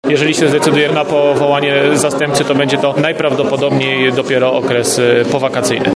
Mówi prezydent Tarnobrzega Łukasz Nowak.